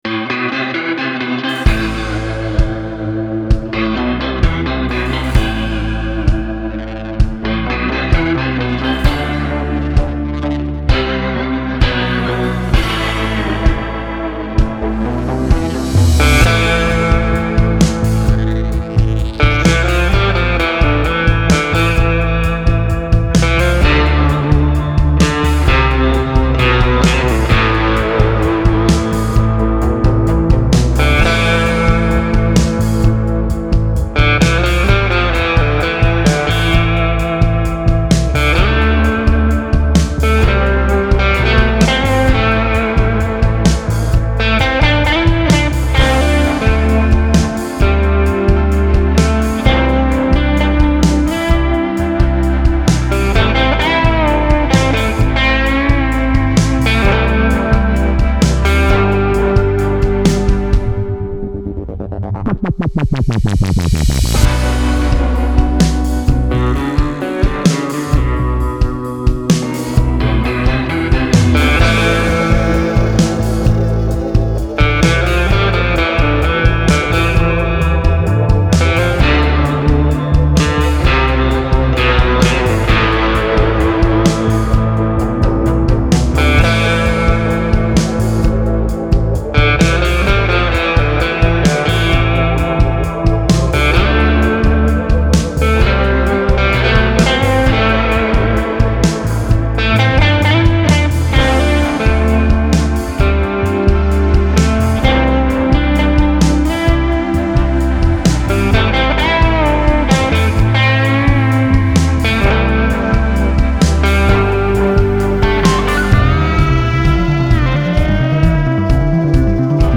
an atmospheric blues track
dramatic reverb-laden vocal performance
guitar, keys and synth